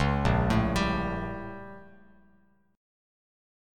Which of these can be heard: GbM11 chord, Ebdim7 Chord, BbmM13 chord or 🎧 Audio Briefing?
BbmM13 chord